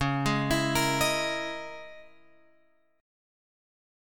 C# Minor 6th Add 9th